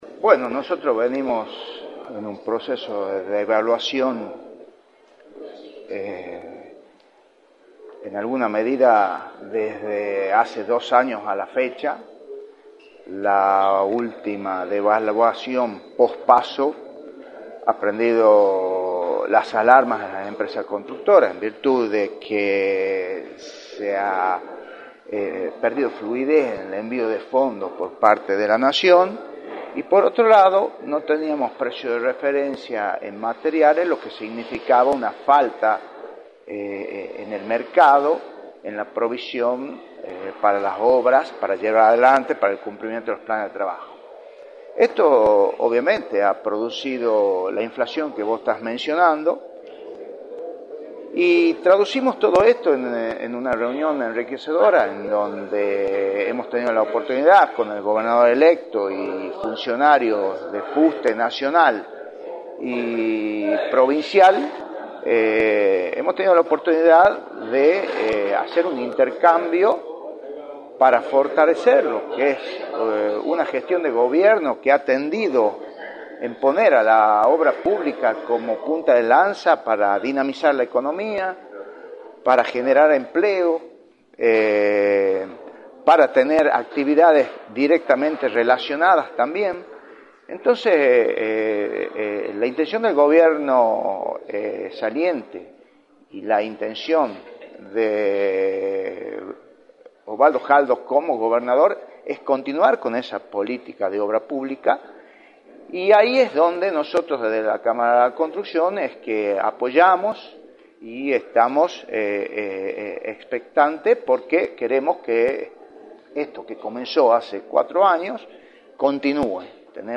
entrevista para «La Mañana del Plata», por la 93.9.